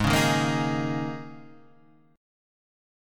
G#sus2sus4 chord {4 4 1 3 4 4} chord